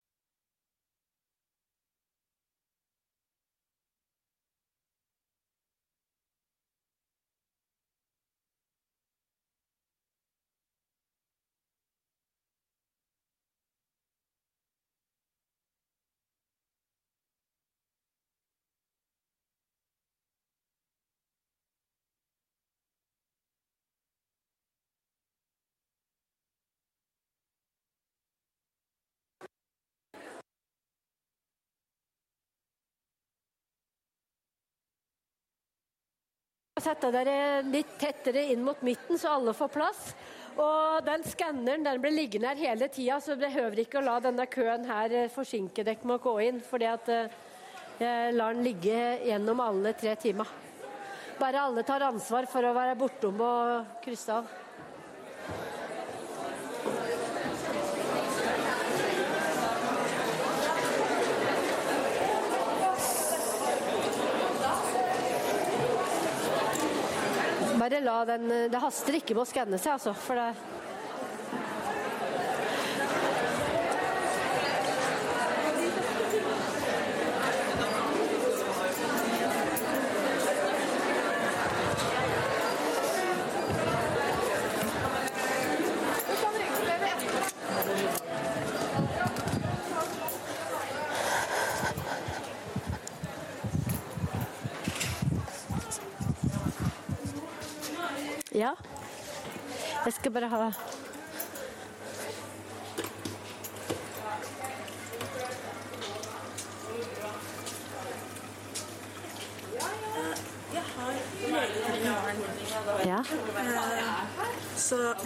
Rom: Store Eureka, 2/3 Eureka